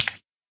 minecraft / sounds / mob / guardian / flop3.ogg
flop3.ogg